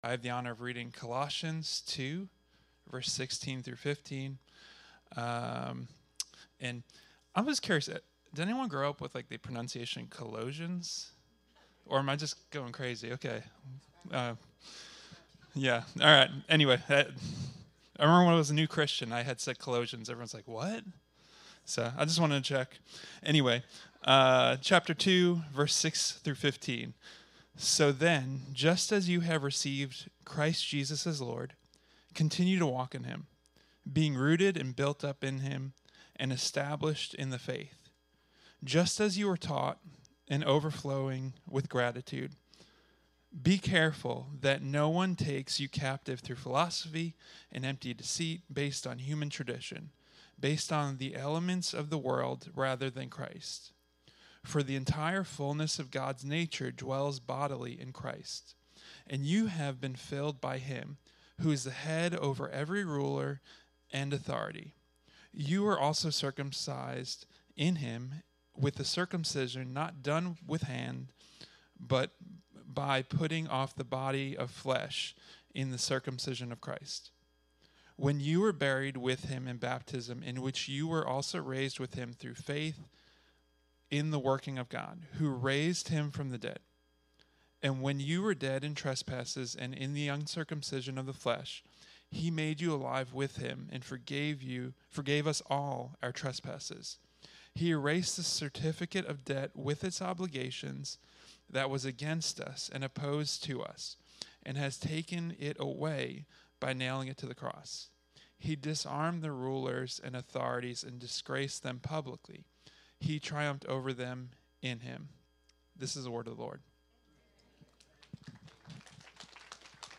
Series: Rooted Service Type: Sunday 10am